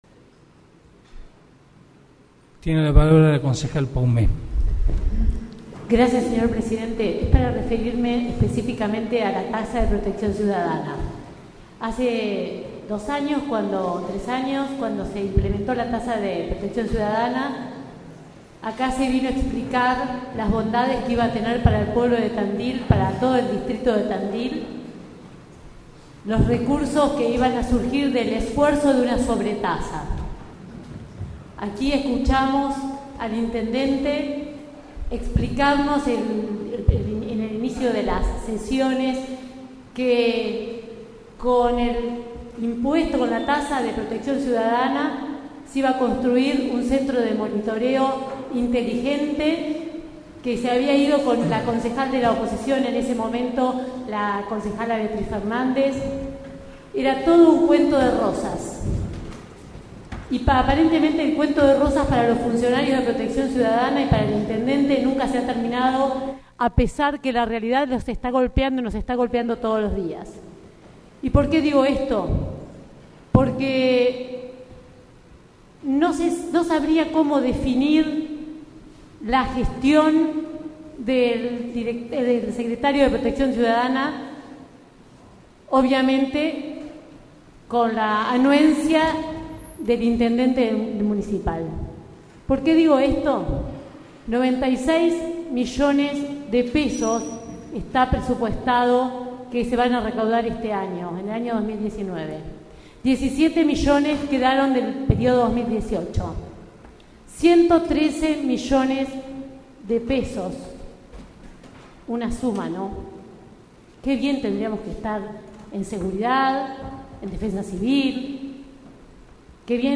Audios de sesiones